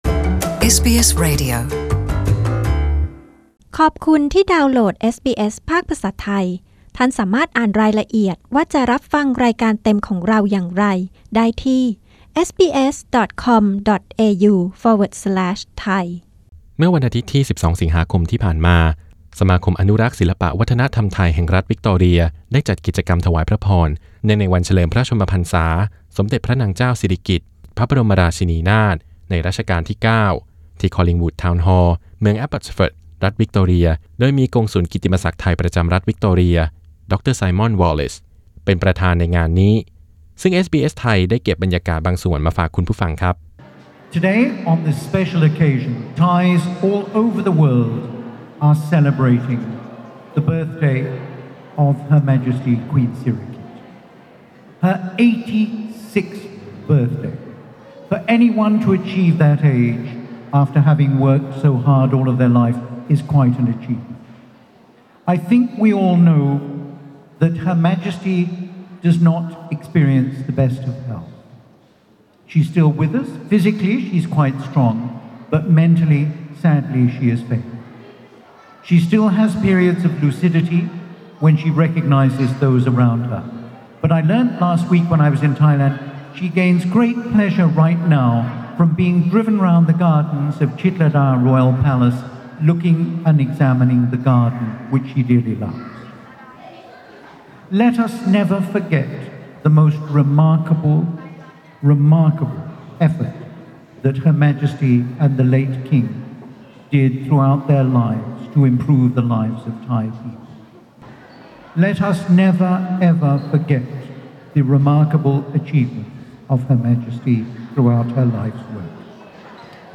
เสียงสัมภาษณ์และบรรยากาศวันแม่ที่เมลเบิร์น 12 ส.ค. 2018